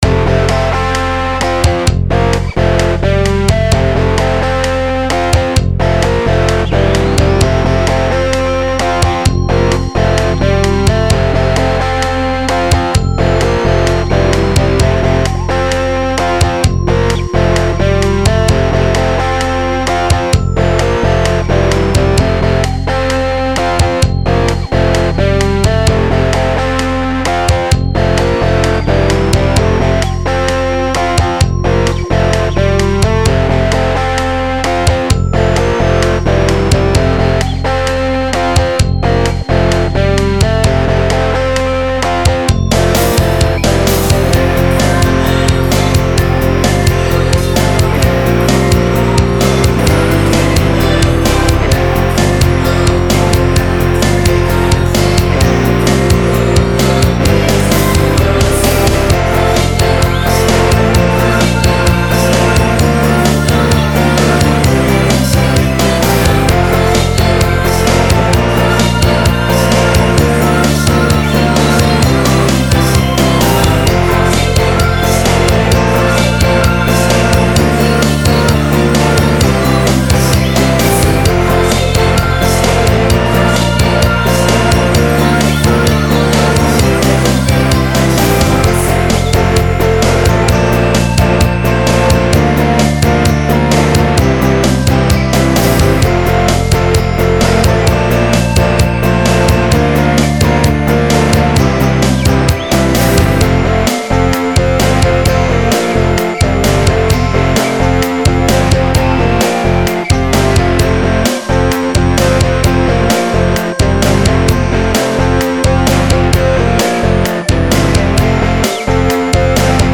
(インスト)